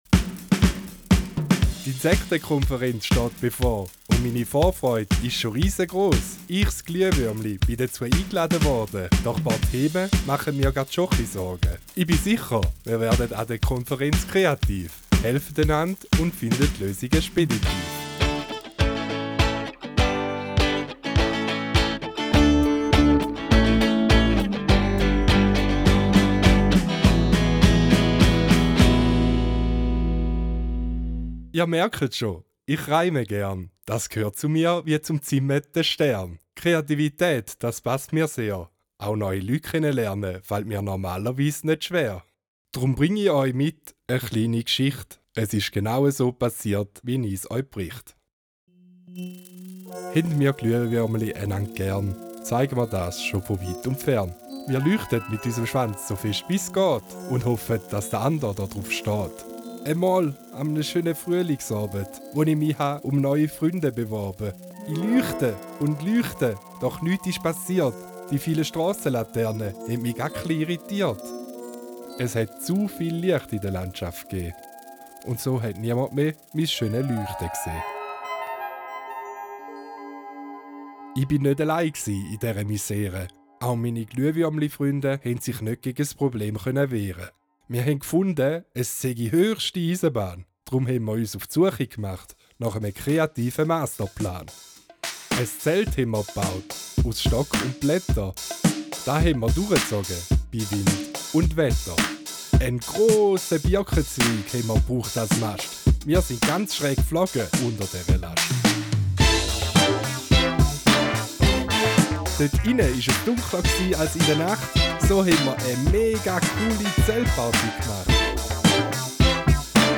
LA Challenge Feuer In der dritten Challenge erzählt euch Glühwürmchen Eldfluga eine Geschichte aus ihrem Leben. Ihr lernt dabei die Schwierigkeiten von Feuer und Licht für die Natur kennen. Startet mit dem Hörspiel: Spielt das Hörspiel über eure Lautsprecher ab.